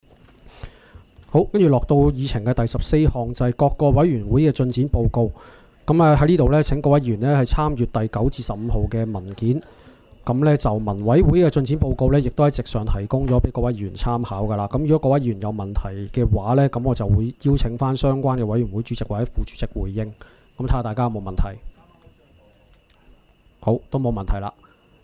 区议会大会的录音记录
地点: 元朗桥乐坊2号元朗政府合署十三楼会议厅